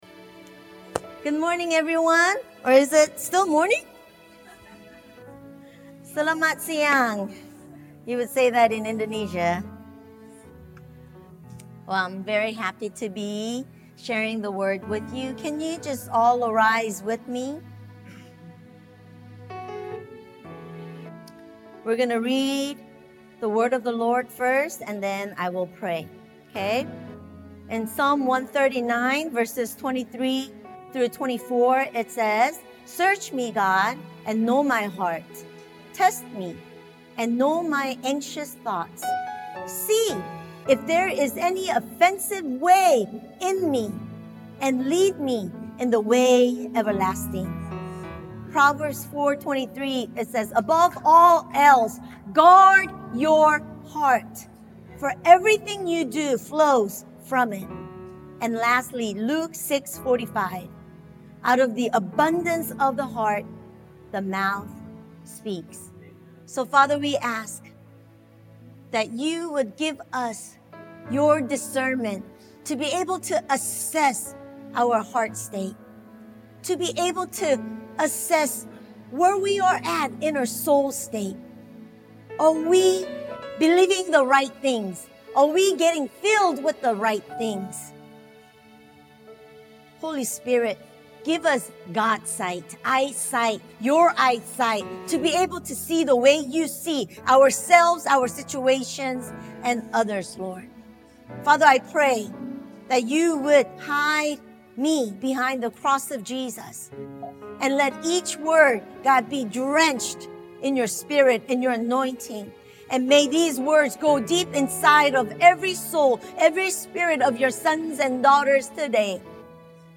This message dives deep into the connection between our beliefs, emotions, and spiritual health.